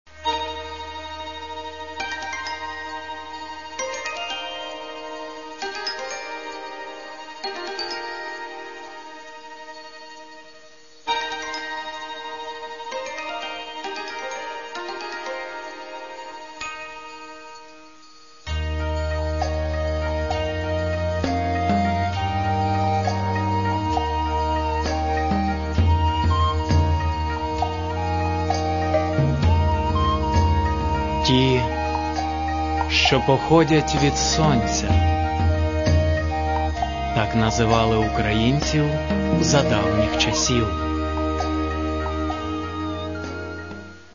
Каталог -> Народна -> Ансамблі народної музики